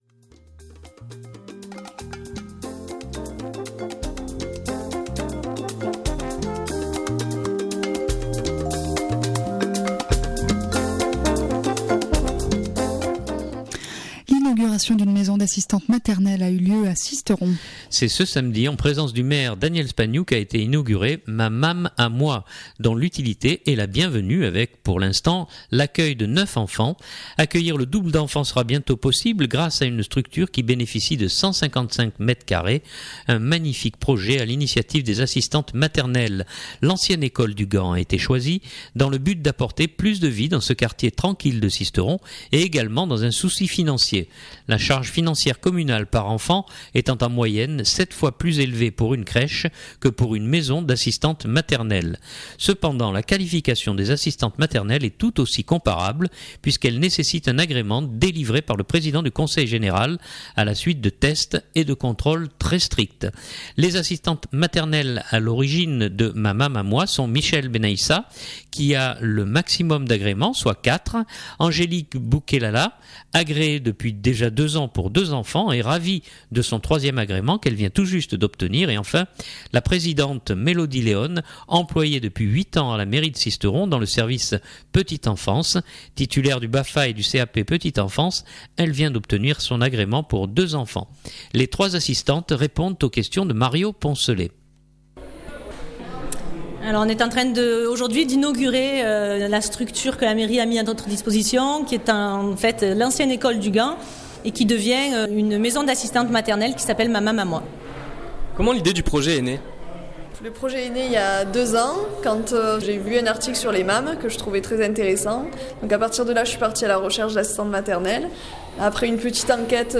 Les trois assistantes répondent aux questions